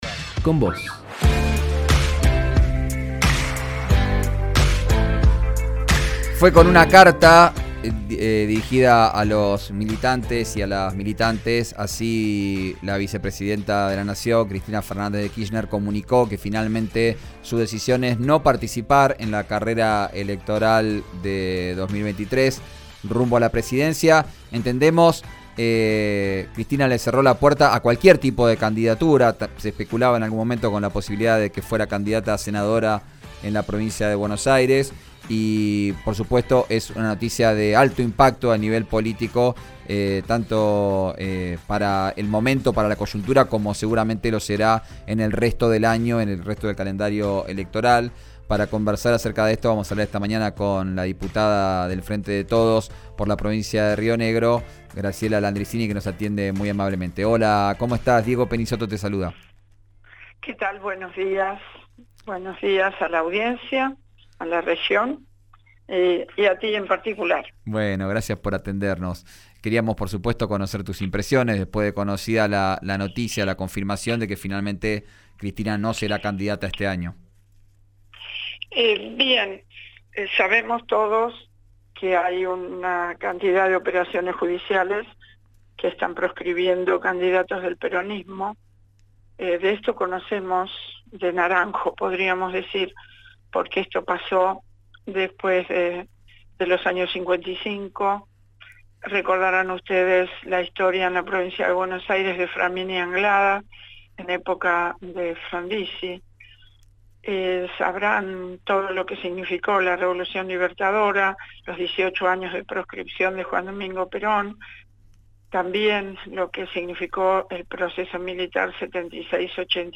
En diálogo con RÍO NEGRO RADIO, expresó la necesidad del peronismo de buscar unidad y estabilidad de cara a las PASO.